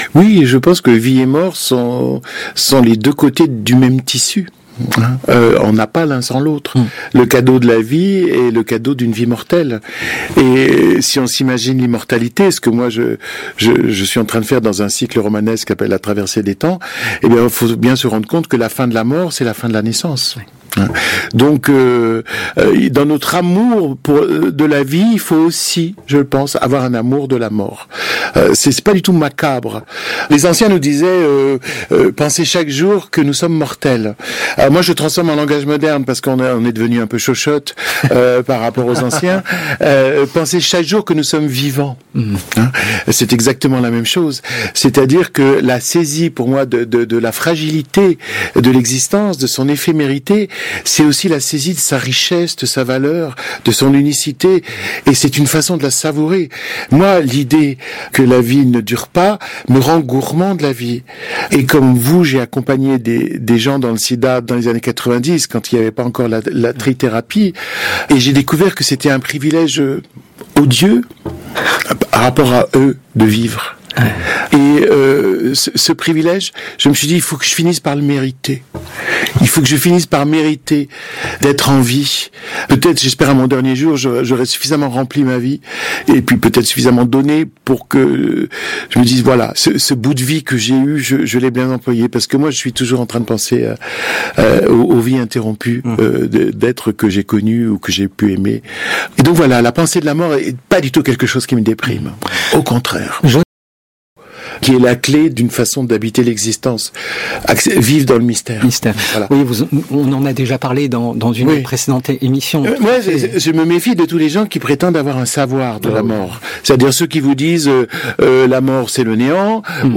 Voici un autre extrait audio de la même entrevue :